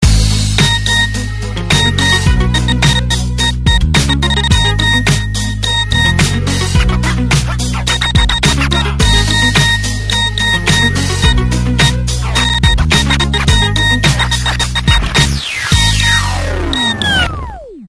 прикольный звоночек на СМС